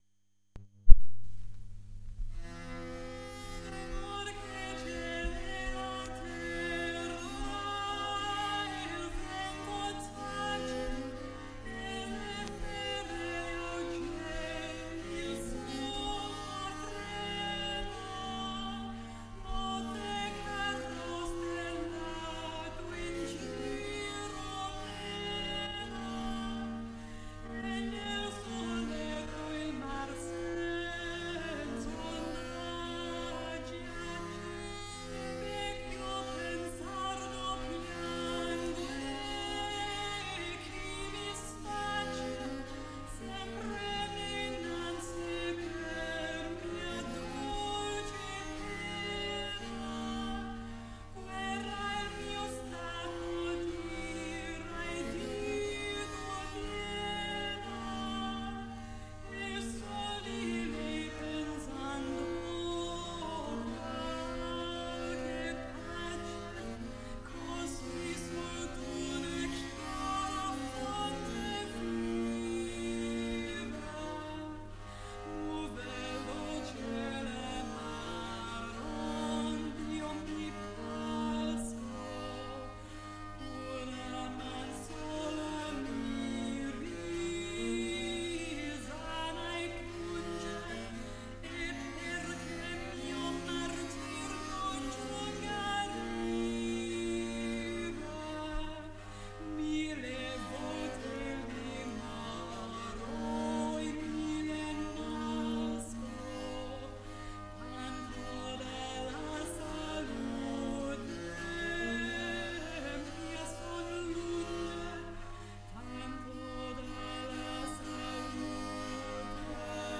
Tromboncino, Hor che 'l ciel e la terra (frottola).mp3